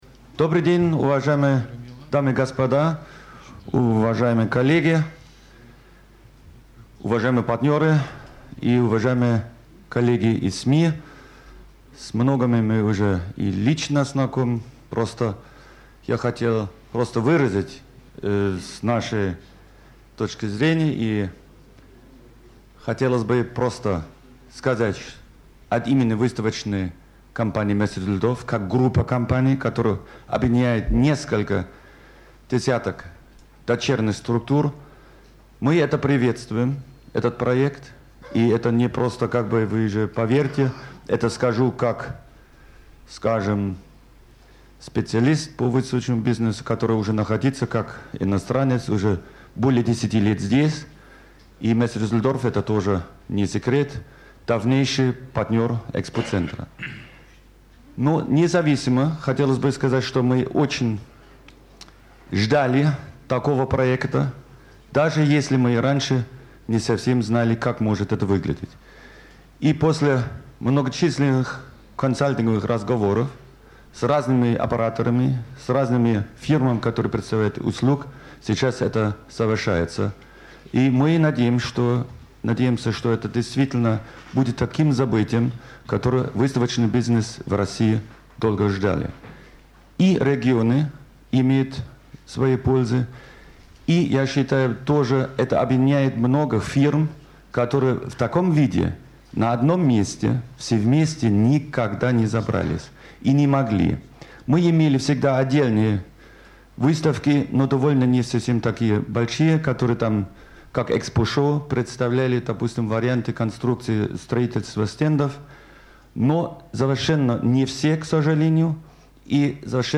12 сентября 2006 г. в 11.00 в Конгресс-центре ЦВК ЭКСПОЦЕНТР состоится пресс-конференция, посвященная запуску нового смотра "Международного Форума выставочной индустрии" 5pEXPO 2007.
Выступление